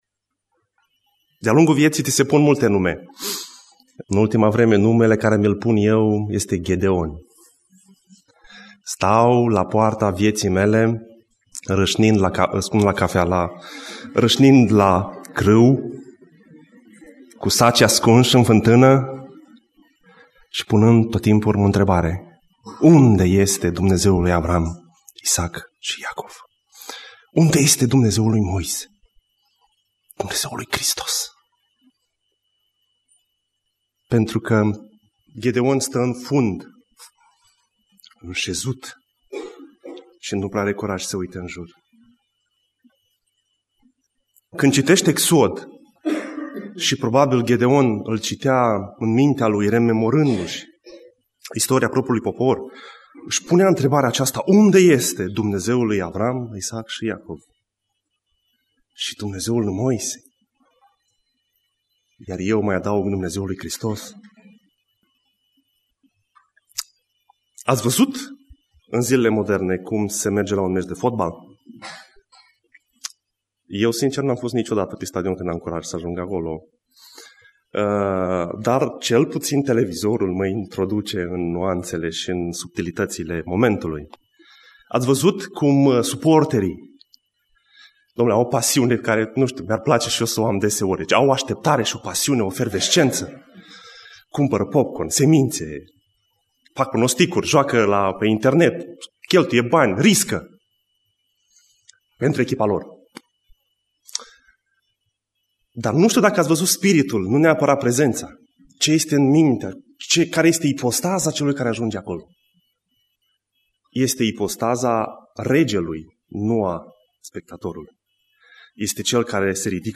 Predica Exegeza Exod 14-18